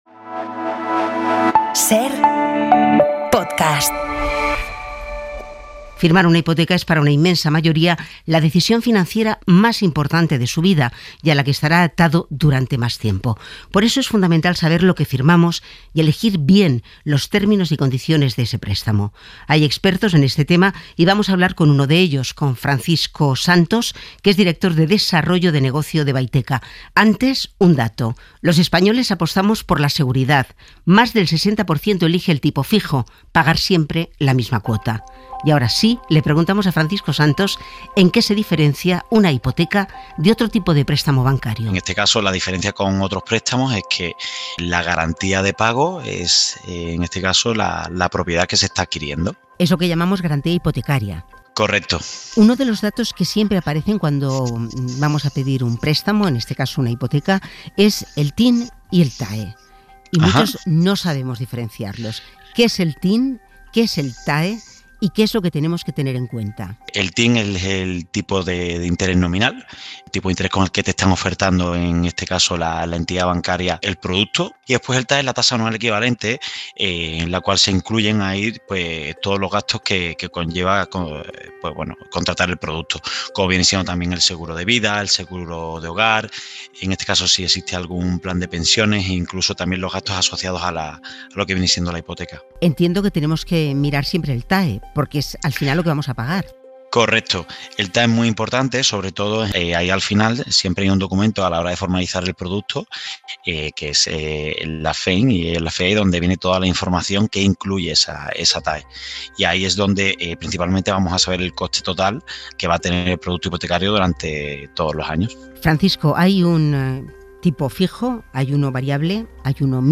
Hablamos con un experto para aclarar dudas básicas cómo qué es el TIN y qué es el TAE y cuál debemos tener en cuenta antes de firmar.